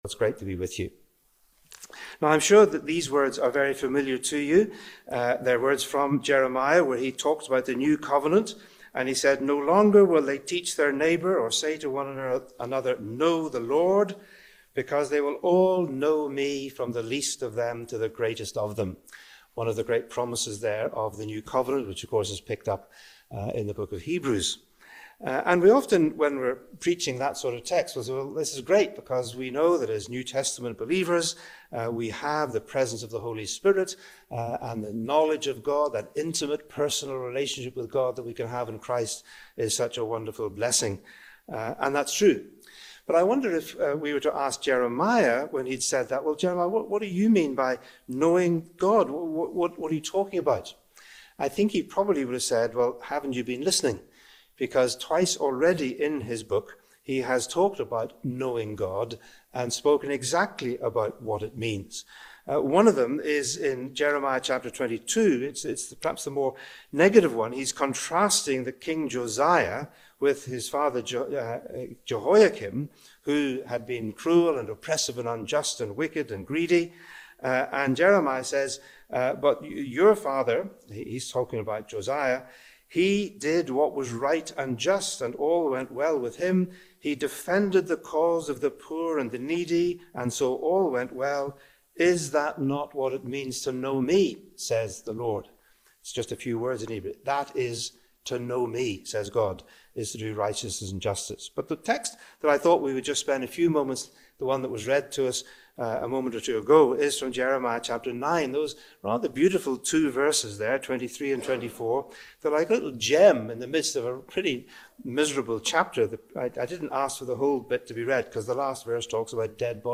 This sermon is especially relevant for: Christian leaders and pastors Students of theology and ministry Anyone wrestling with the relationship between faith, justice, and discipleship